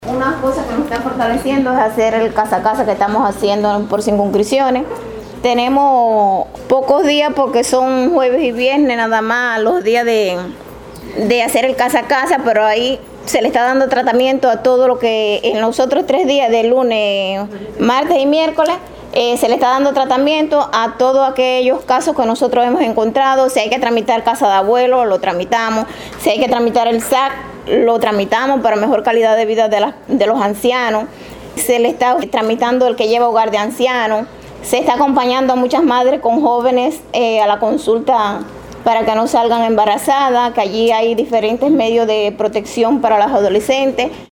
Un análisis profundo sobre la labor del trabajador social en la comunidad y la situación del empleo laboral, signaron los debates en el balance del quehacer durante el 2023 de la Dirección Municipal de Trabajo y Seguridad Social este viernes en Jobabo.